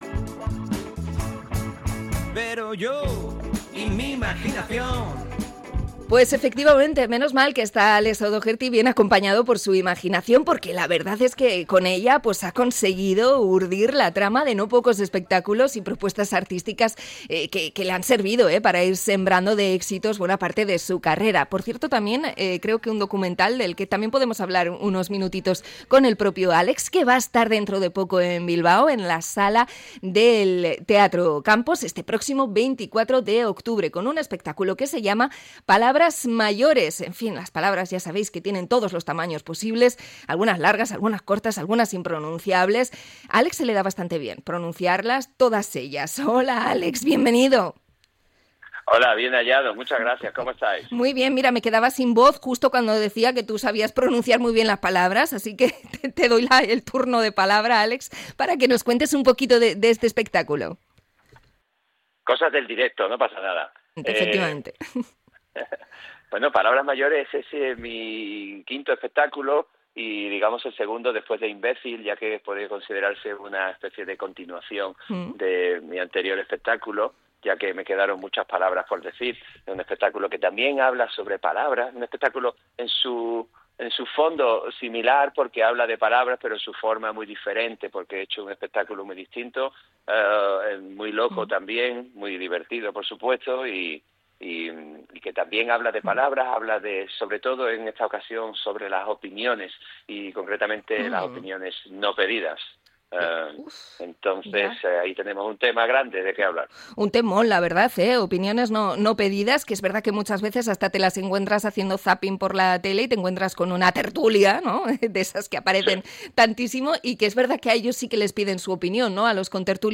Entrevista al artista por su actuación en el Campos este fin de semana